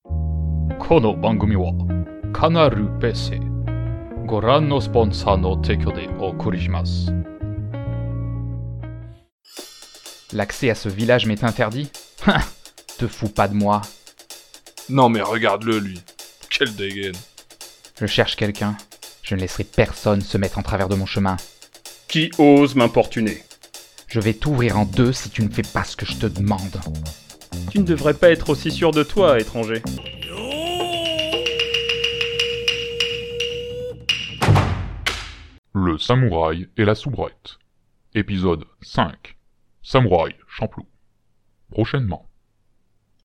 Bande Annonce : Épisode 5
Pour la première fois, vous allez entendre d’autres personnes participer aux sketches, car nous avons fait appel à des guests !